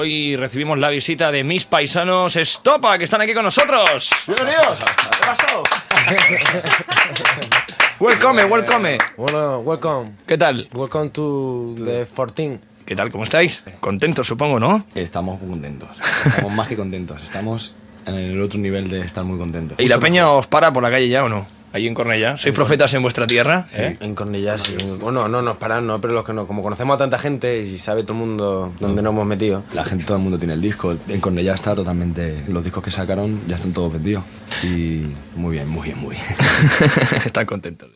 Radio show
Primera entrevista a la cadena 40 Principales als germans David i Jose Muñoz del grup Estopa que presenten el seu primer disc